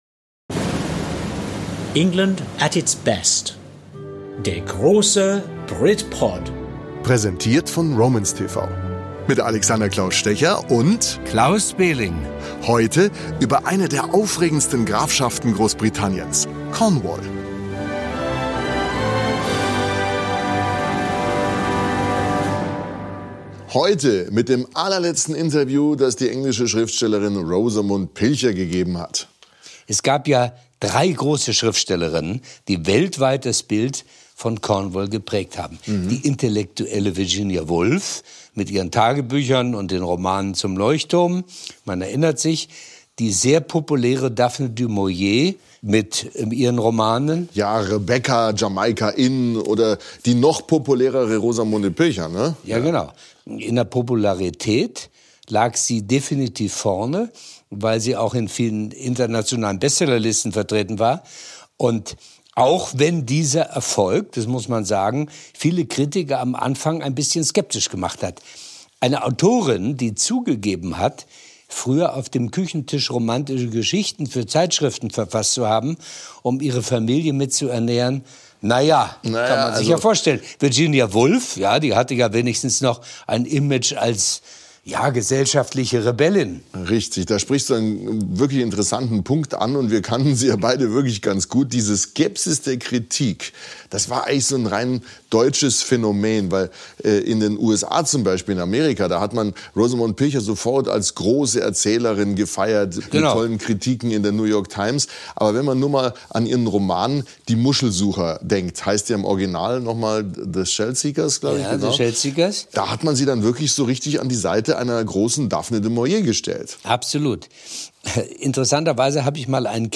Ein bewegendes, intensives und hoch unterhaltsames Gespräch mit einer außergewöhnlichen Frau, deren Buchverfilmungen mehr als eine halbe Milliarde Menschen gesehen haben.